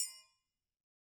Triangle6-HitM_v2_rr1_Sum.wav